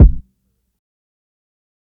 HFMKick9.wav